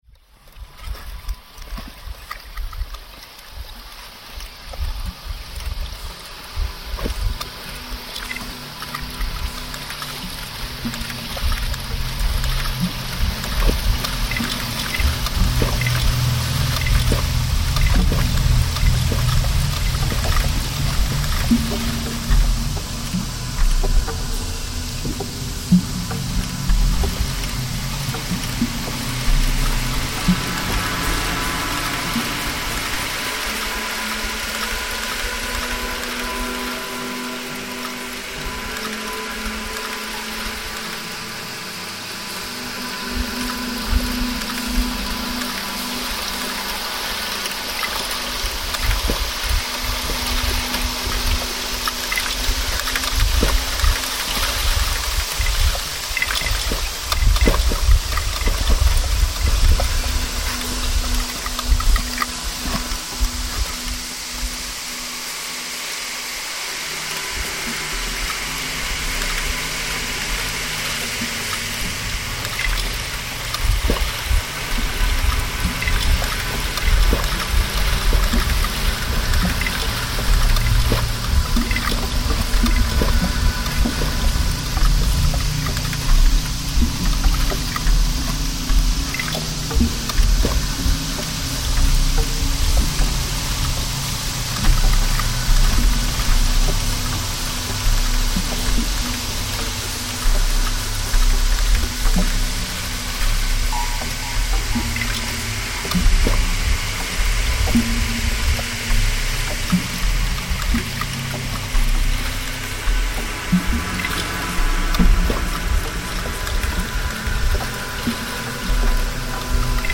Kyrgyzstan Son Kul ice lake reimagined